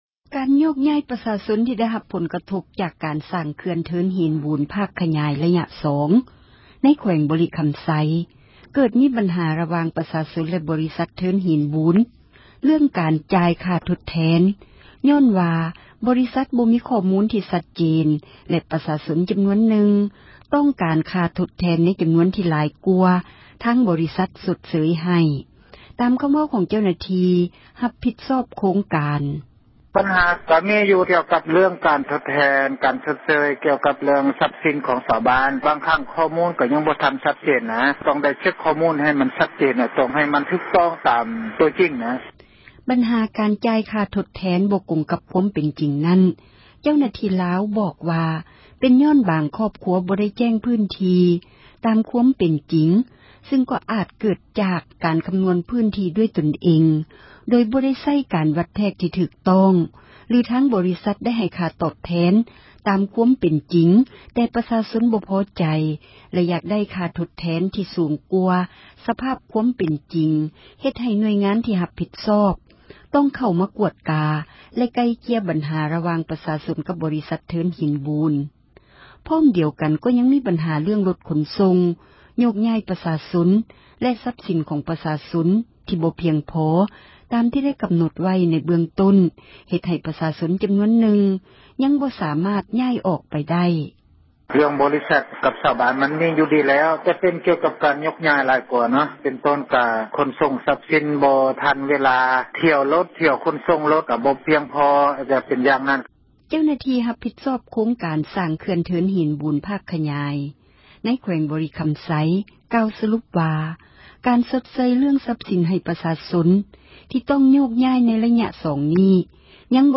ຕາມຄໍາເວົ້າຂອງ ເຈົ້າໜ້າທີ່ຮັບ ຜິດຊອບໂຄງການ: